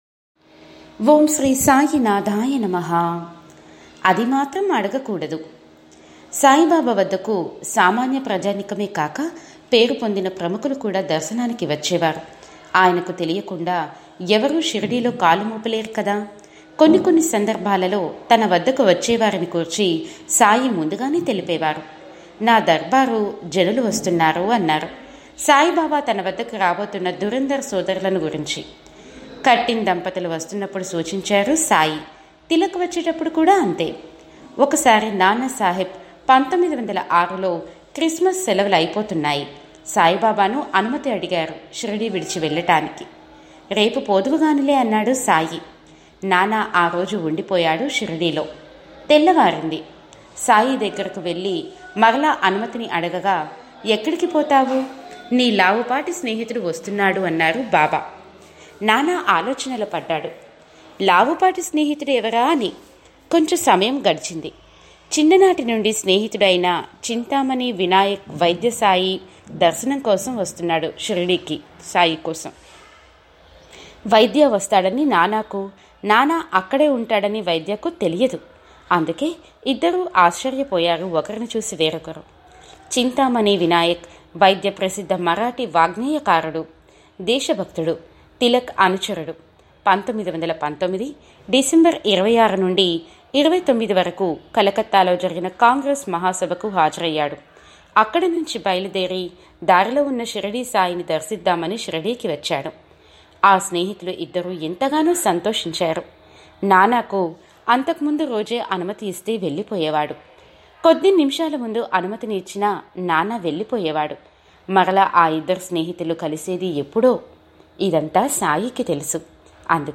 Voice Support By: